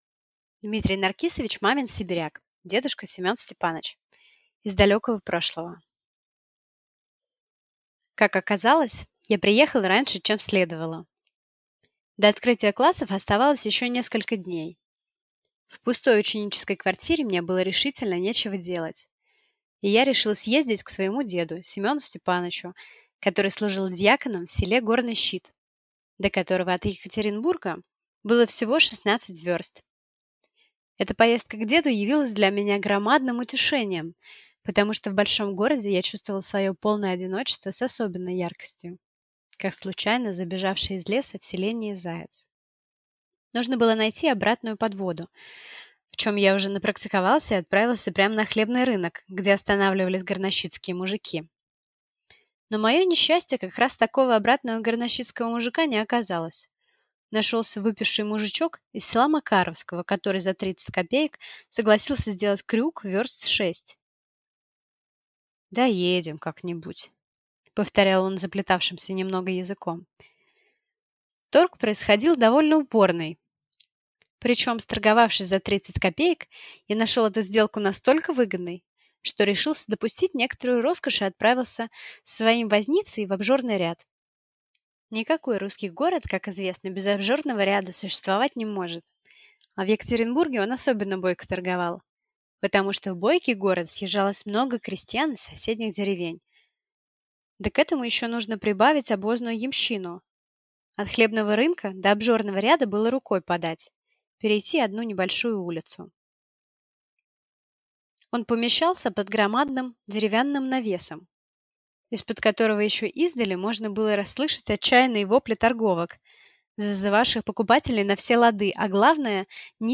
Аудиокнига Дедушка Семен Степаныч | Библиотека аудиокниг